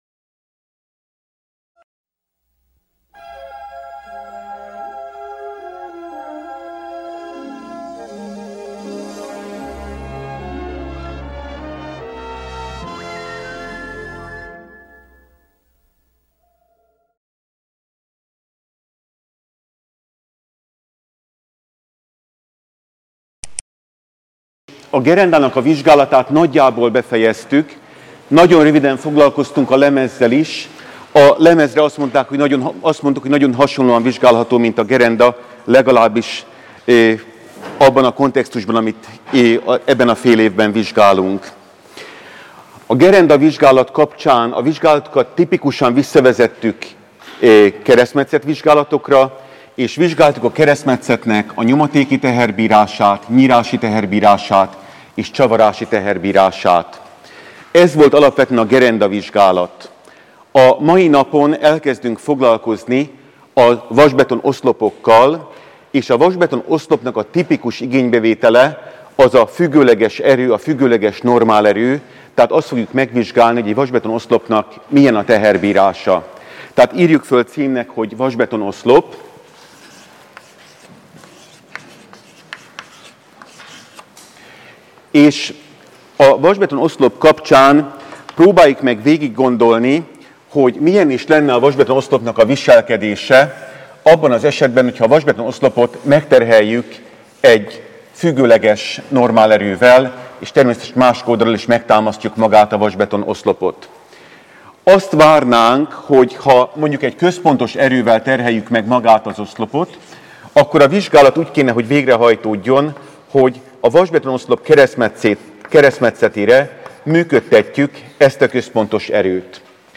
Vasbeton I.- 8. előadás